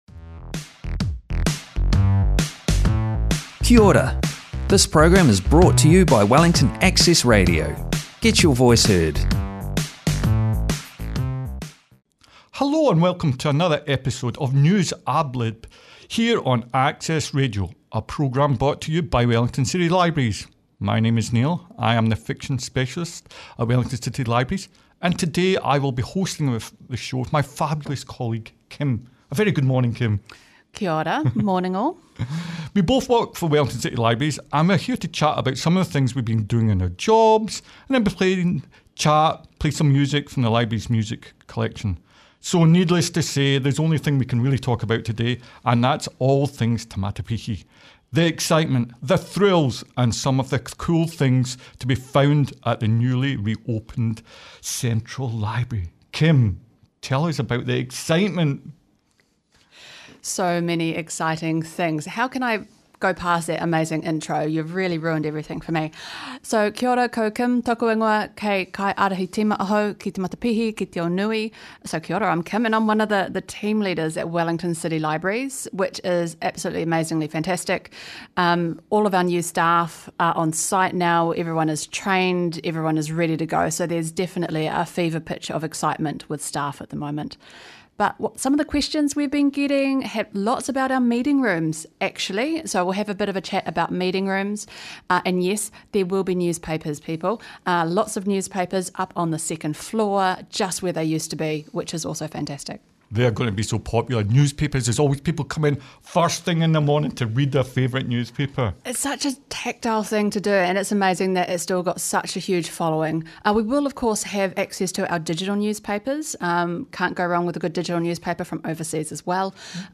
And yes, we will continue playing music from around the world and of course local talent!